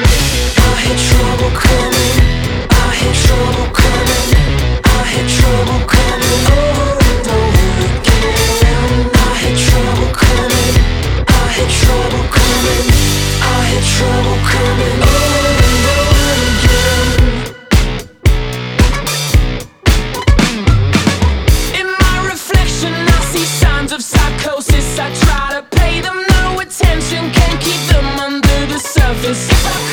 Genre: Alternative